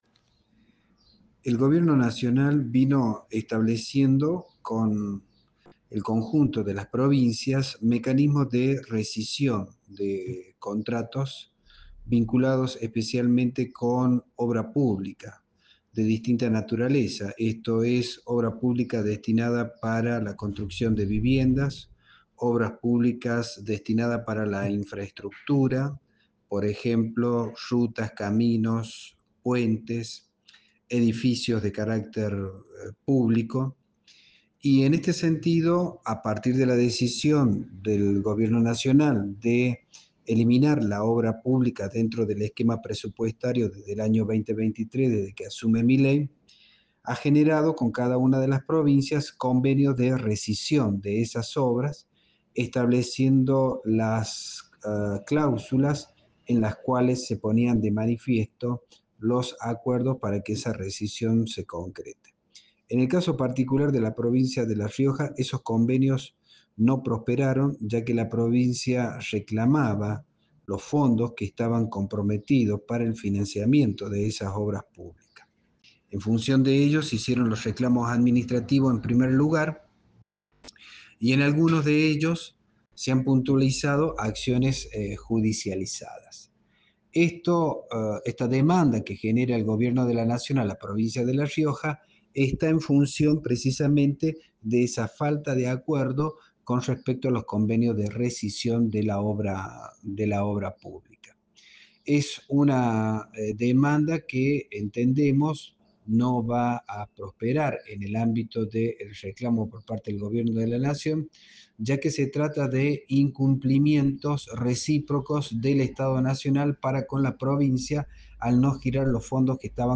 En un diálogo exclusivo con este medio